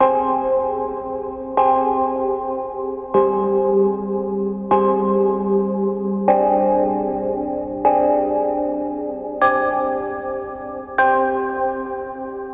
Sea Sounds from BC » crunch
描述：Foot crunching a crab in the sand
标签： crab crunch crush smash
声道立体声